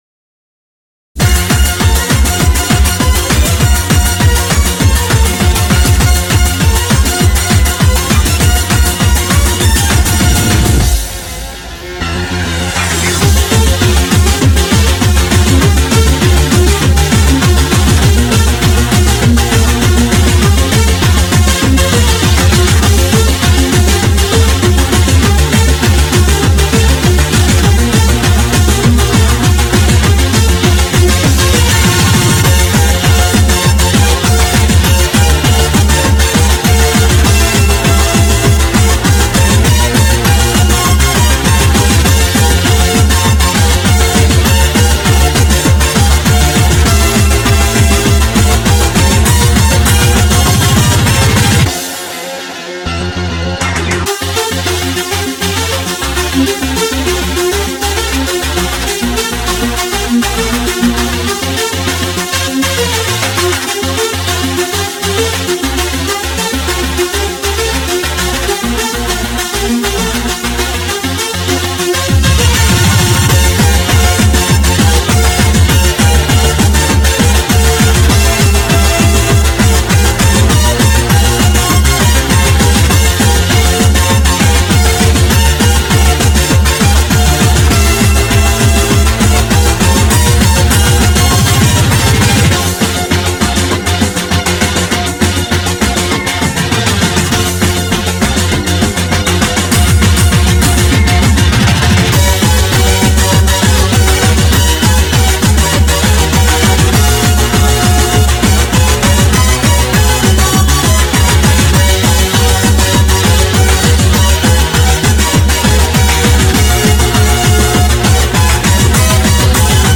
BPM200
Audio QualityPerfect (High Quality)
speed remix